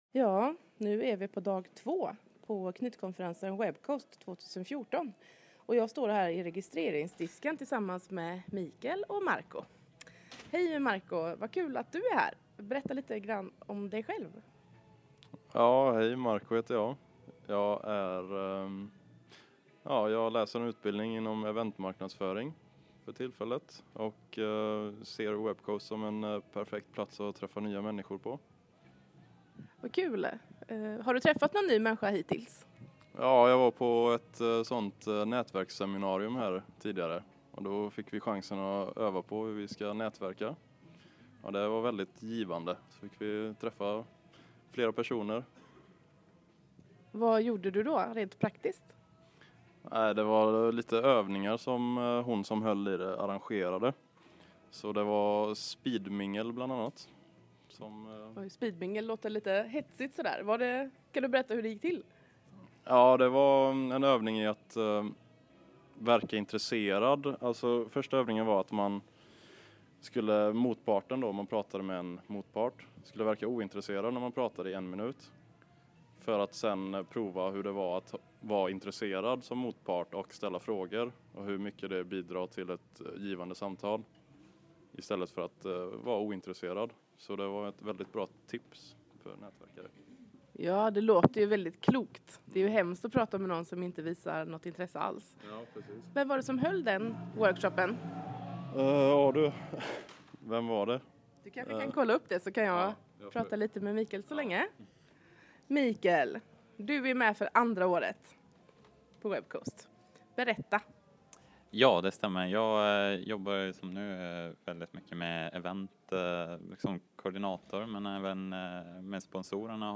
Rapport
i registreringsdisken på knytkonferensen WebCoast 2014.